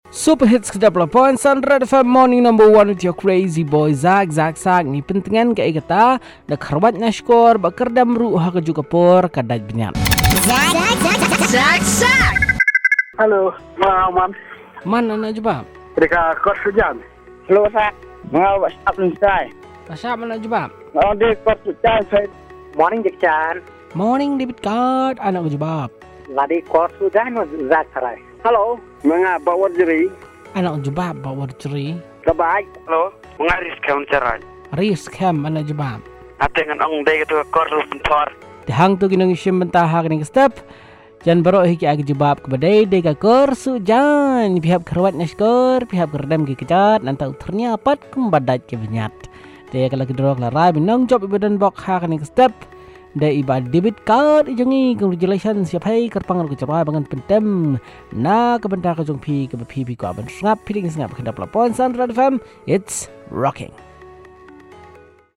calls and results